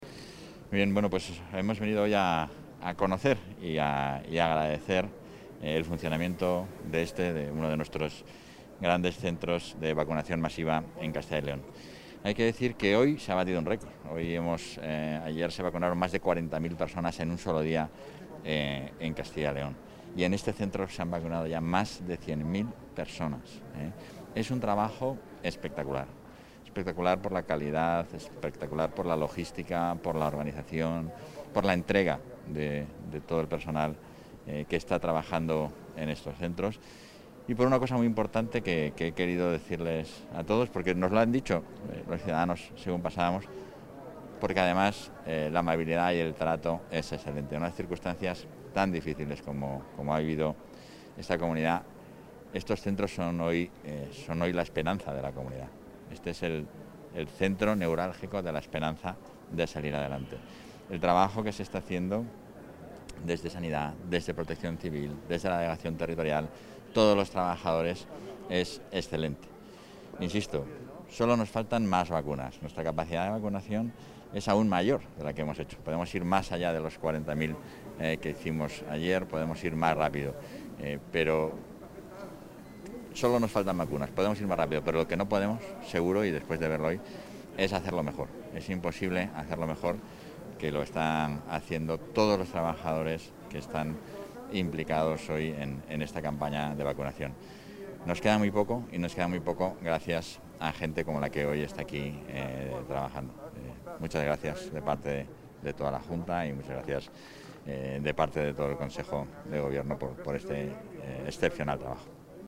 Audio vicepresidente.
El vicepresidente, portavoz y consejero de Transparencia, Ordenación del Territorio y Acción Exterior, Francisco Igea, realiza una visita institucional al Punto de Vacunación a Gran Escala, en el Centro Cultural Miguel Delibes, en Valladolid.